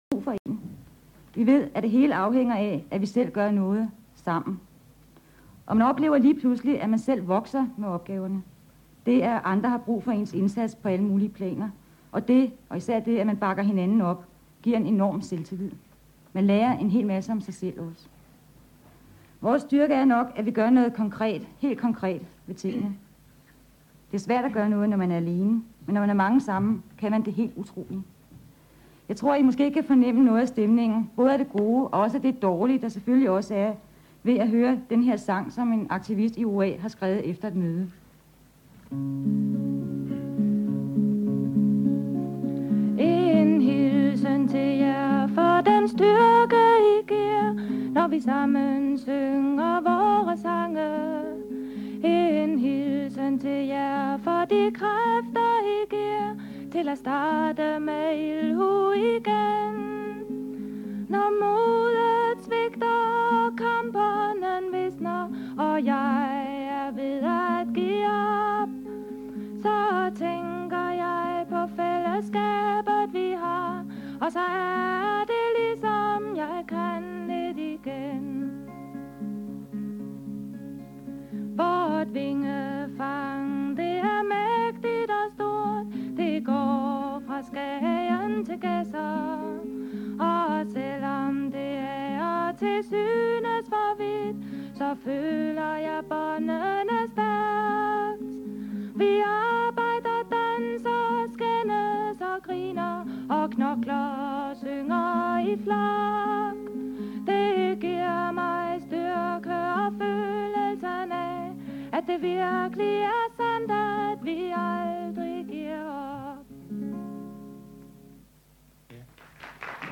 Fra udsendelsen 'Håbet' på P1 den 16. december 1981.